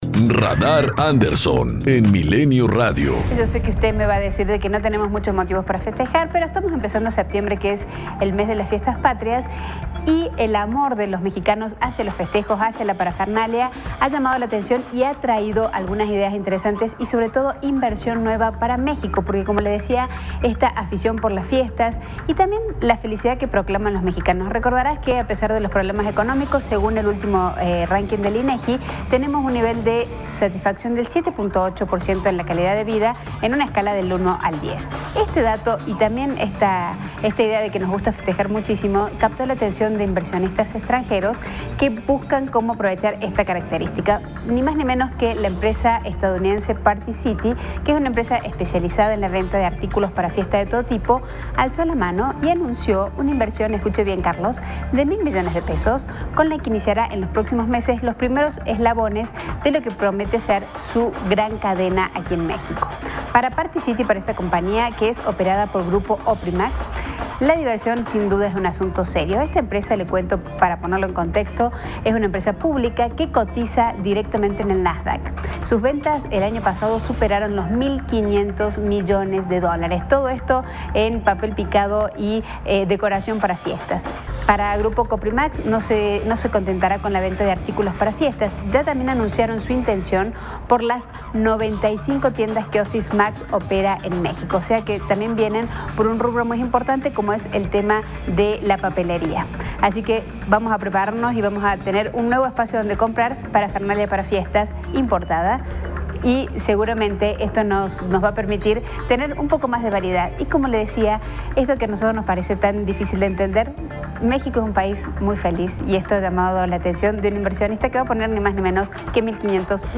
COMENTARIO EDITORIAL 010915